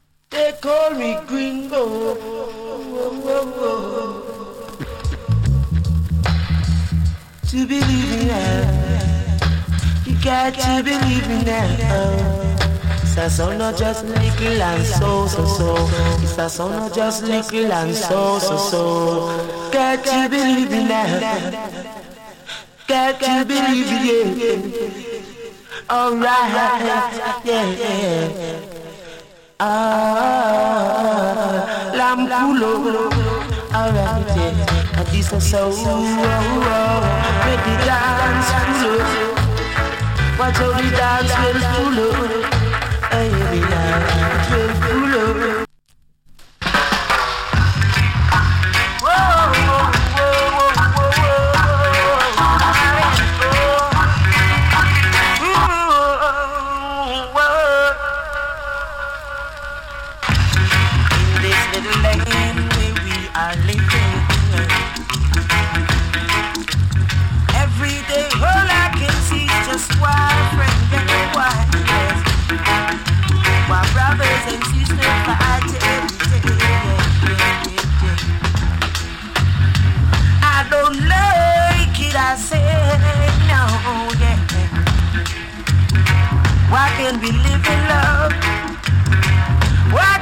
ROOTS VOCAL.